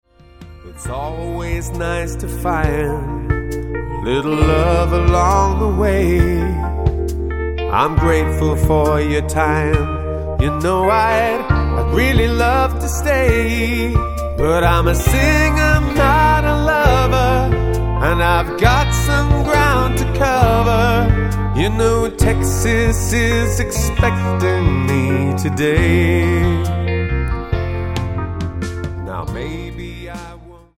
Tonart:Bb Multifile (kein Sofortdownload.
Die besten Playbacks Instrumentals und Karaoke Versionen .